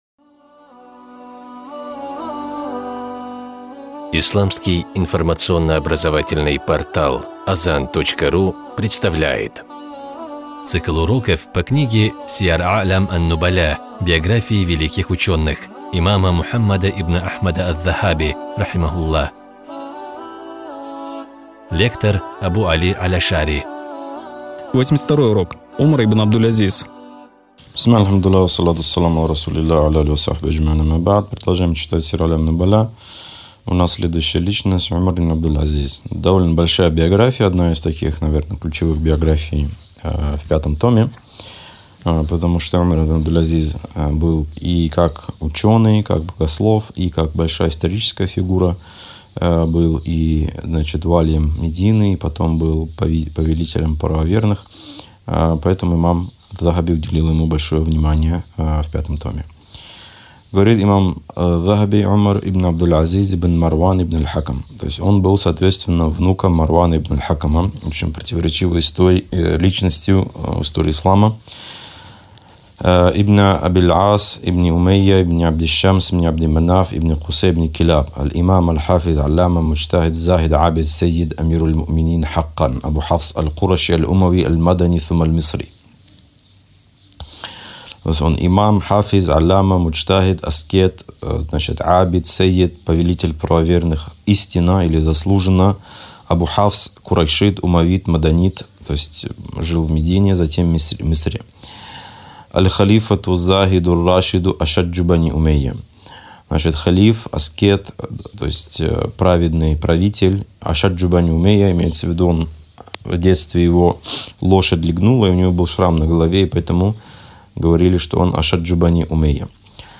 Цикл уроков по книге великого имама Аз-Захаби «Сияр а’лям ан-нубаля». Биографии исламских ученых для мусульман — не просто история, но и пример для подражания верующих, средство для улучшения их нрава.